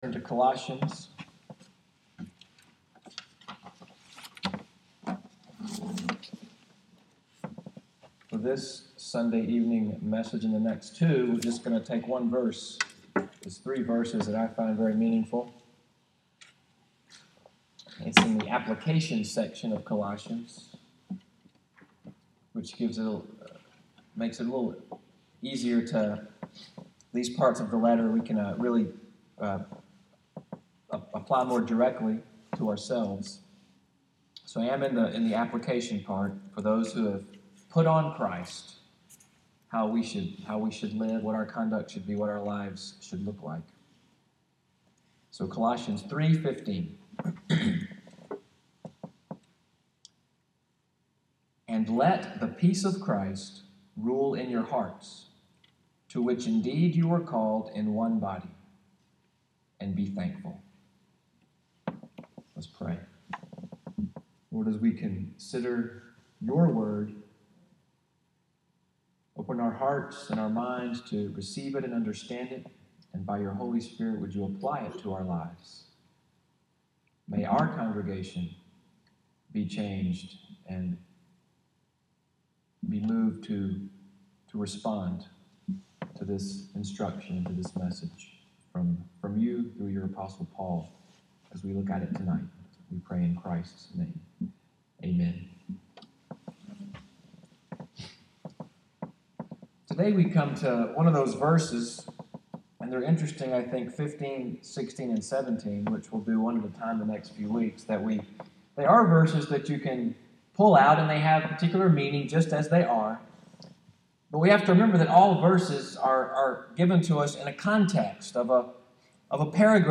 EVENING WORSHIP at NCPC, “The Rule of Peace,” February 12, 2017.